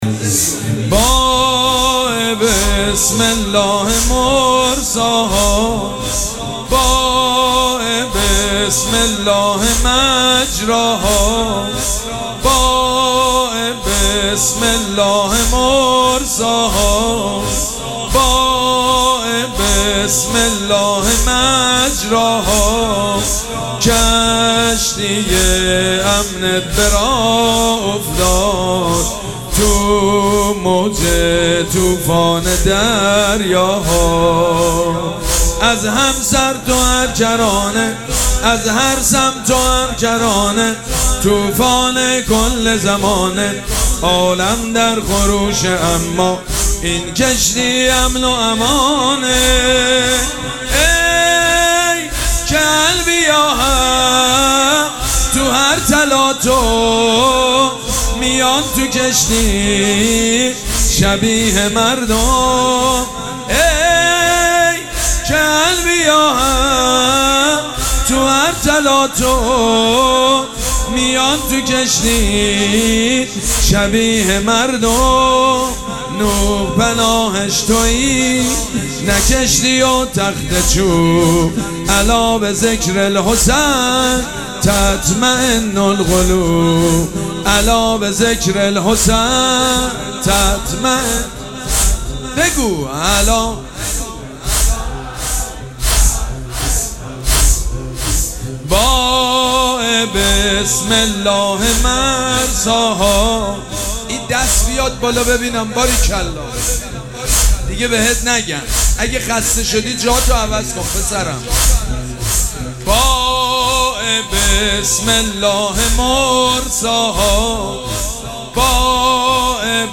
شب دوم مراسم عزاداری اربعین حسینی ۱۴۴۷ شنبه ۱۸ مرداد ۱۴۰۴ | ۱۵ صفر ۱۴۴۷ موکب ریحانه الحسین سلام الله علیها